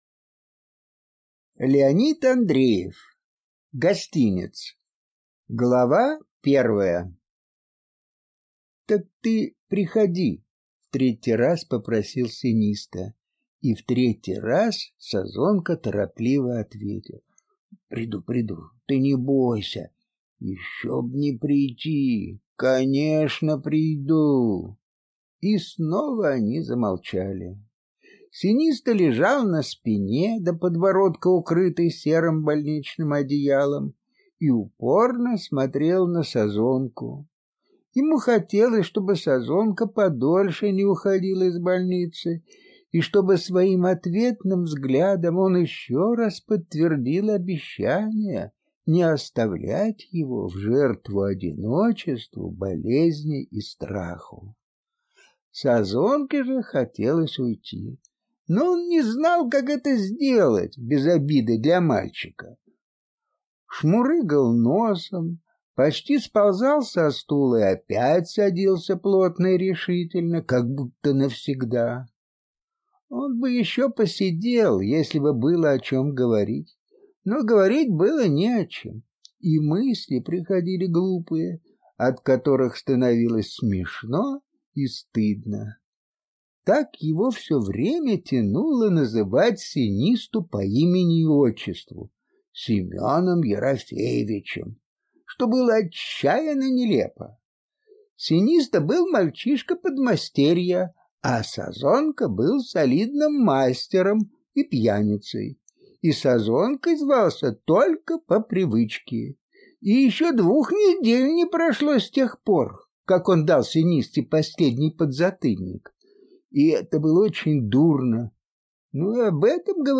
Аудиокнига Гостинец | Библиотека аудиокниг